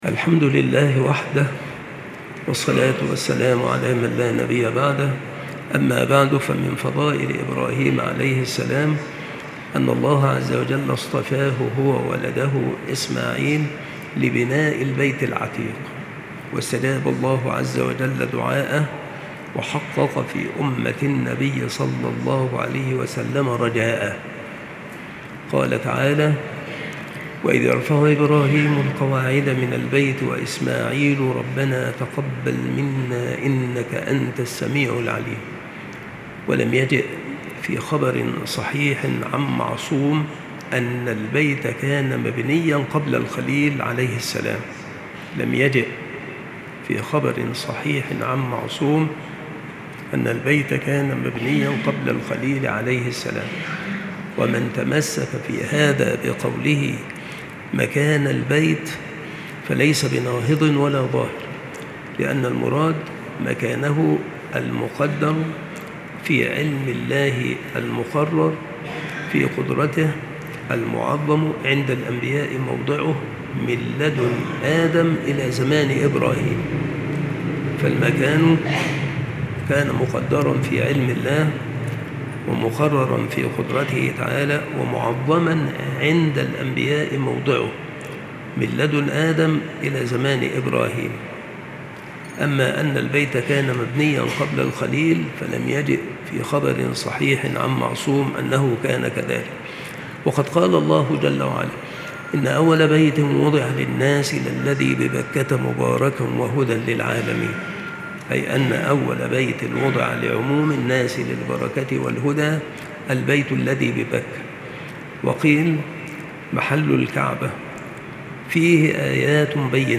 • مكان إلقاء هذه المحاضرة : بالمسجد الشرقي - سبك الأحد - أشمون - محافظة المنوفية - مصر